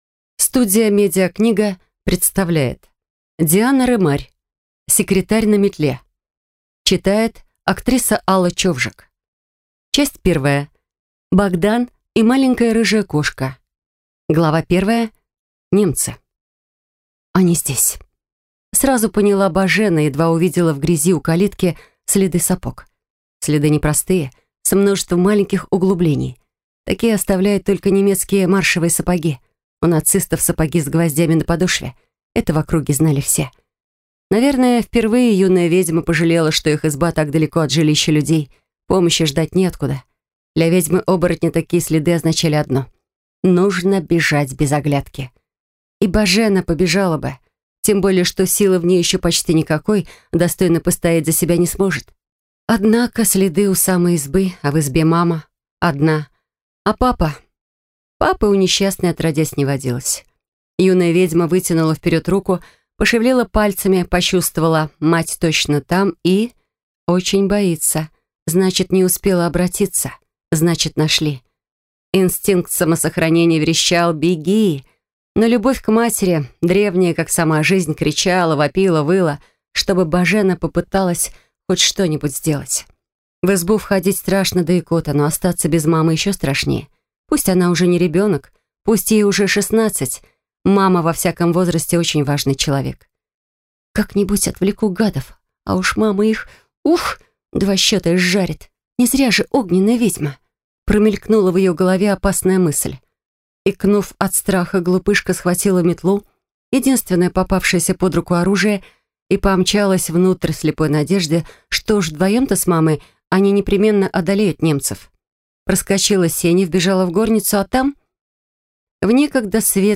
Аудиокнига Секретарь на метле | Библиотека аудиокниг
Прослушать и бесплатно скачать фрагмент аудиокниги